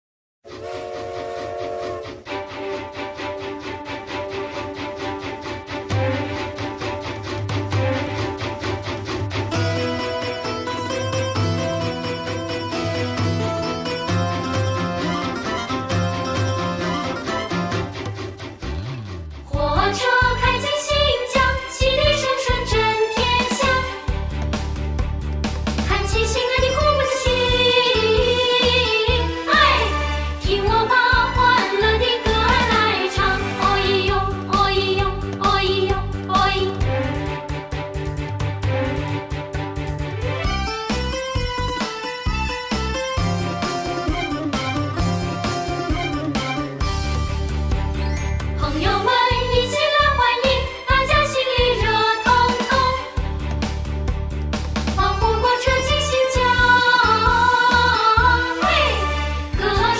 范唱：火车来了